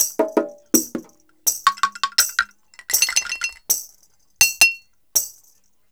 81-PERC1.wav